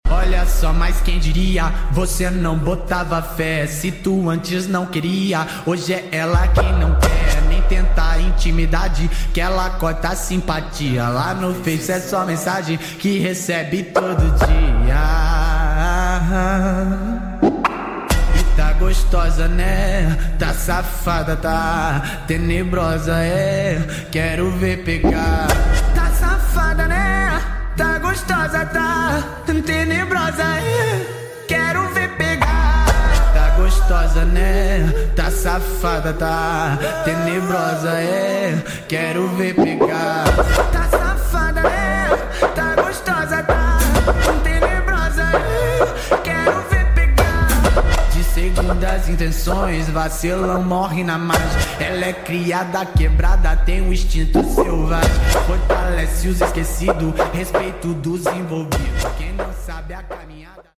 #8daudio